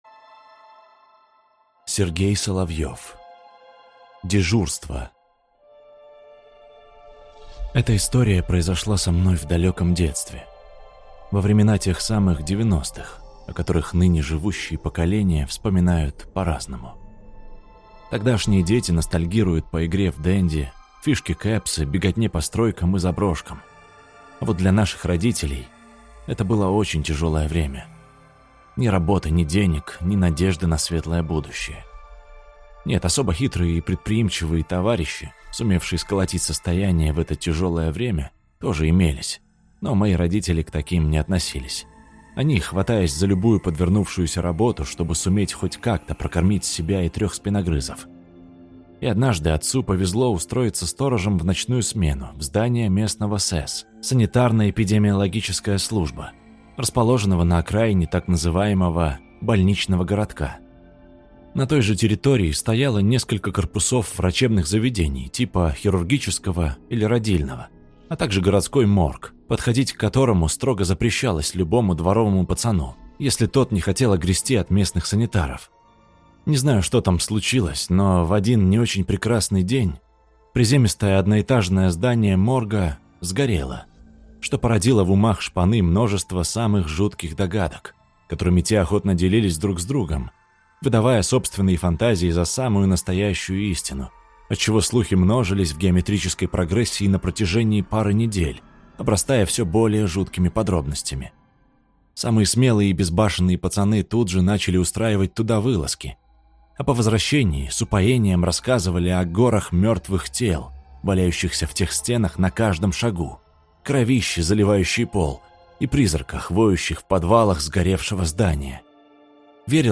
Россказчик классный.